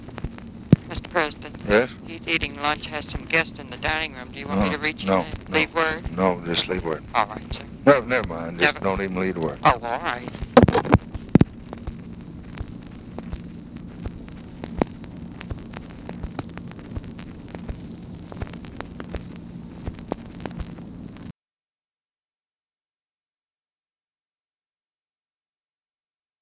Conversation with TELEPHONE OPERATOR, April 4, 1964
Secret White House Tapes